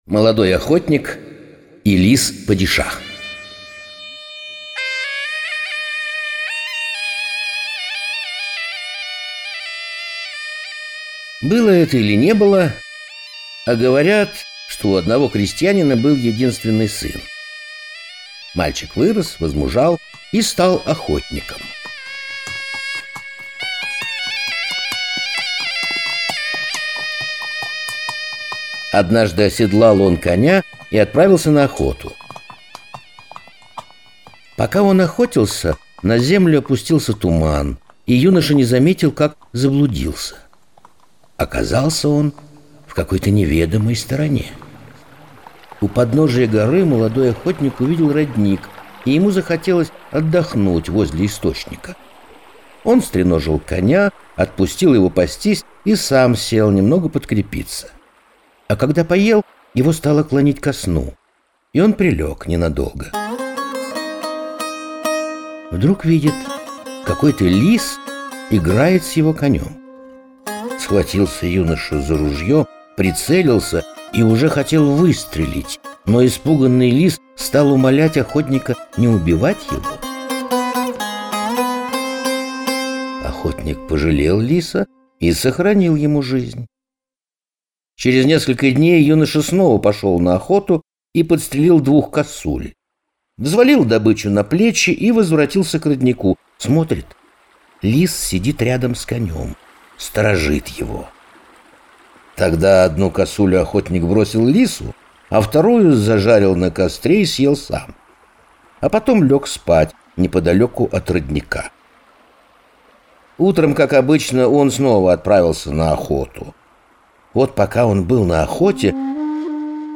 Молодой охотник и лис-падишах - крымскотатарская аудиосказка. Однажды молодой охотник заблудился во время тумана и остановился отдохнуть.